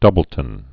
(dŭbəl-tən)